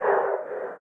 breath2gas.ogg